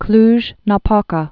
(klzhnä-pôkä)